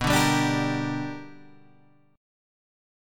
B Minor Major 7th Sharp 5th